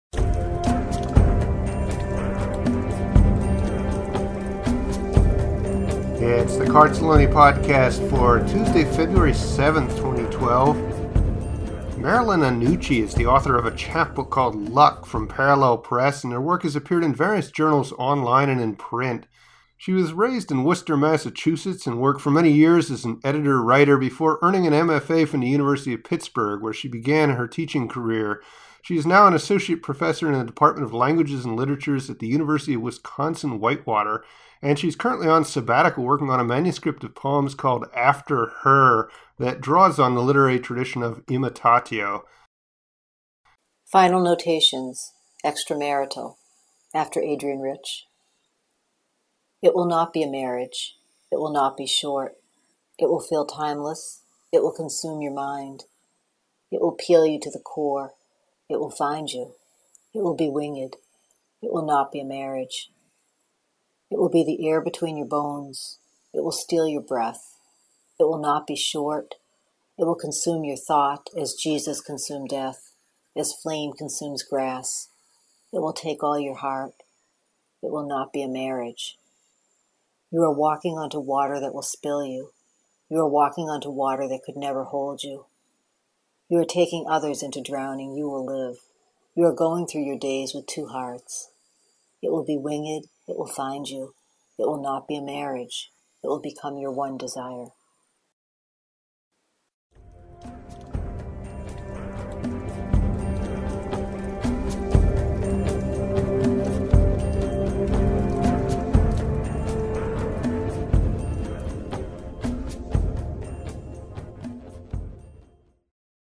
Great poem and nice job reading it!